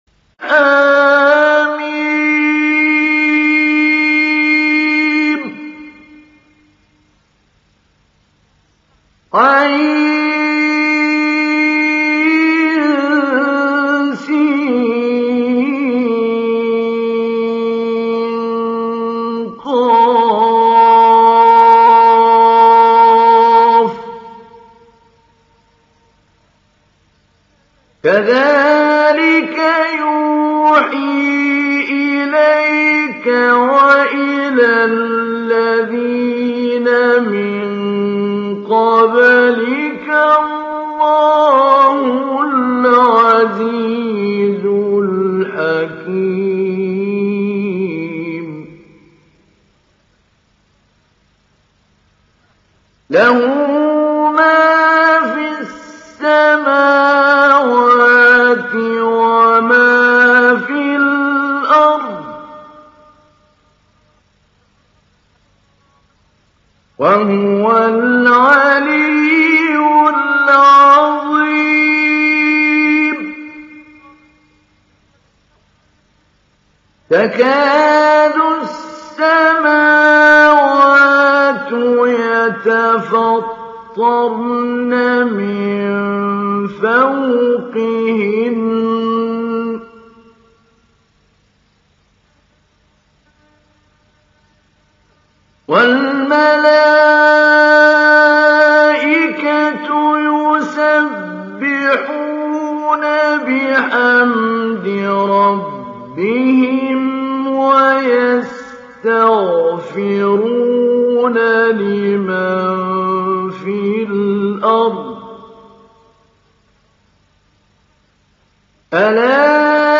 Surat Ash Shura Download mp3 Mahmoud Ali Albanna Mujawwad Riwayat Hafs dari Asim, Download Quran dan mendengarkan mp3 tautan langsung penuh
Download Surat Ash Shura Mahmoud Ali Albanna Mujawwad